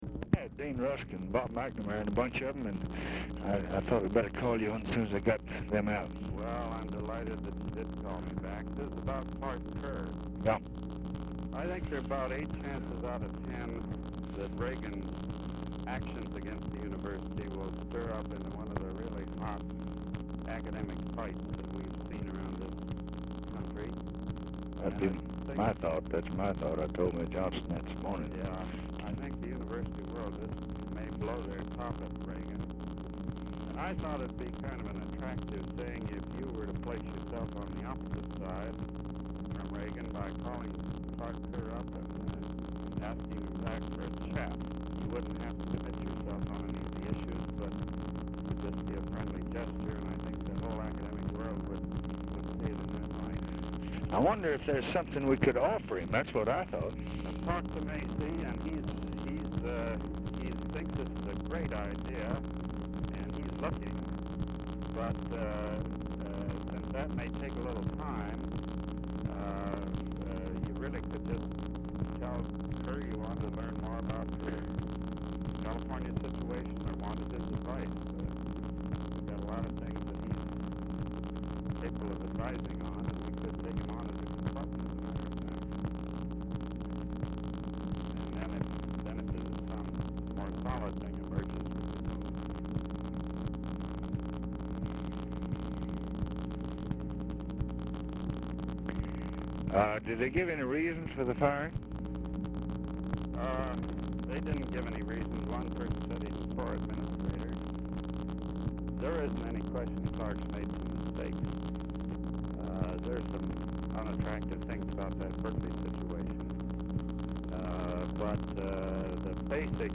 Telephone conversation # 11390, sound recording, LBJ and JOHN GARDNER, 1/21/1967, 1:16PM
RECORDING STARTS AFTER CONVERSATION HAS BEGUN; POOR SOUND QUALITY
Dictation belt